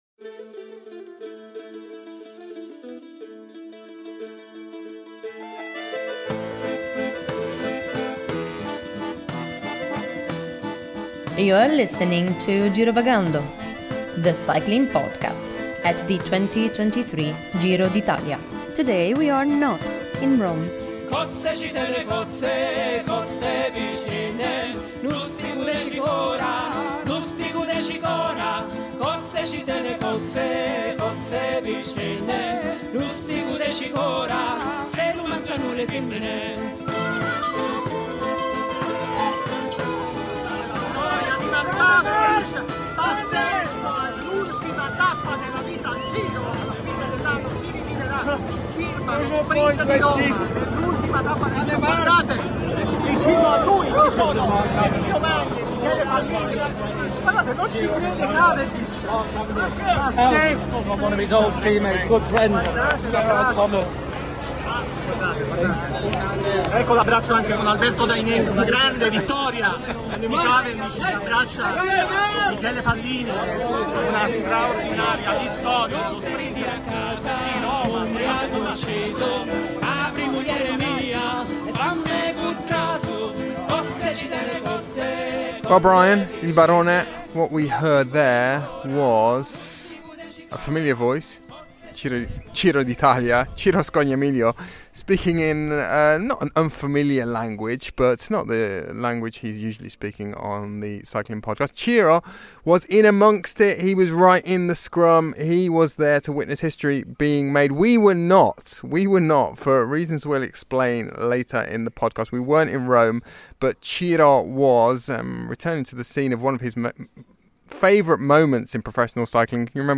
News, Sports, Sports News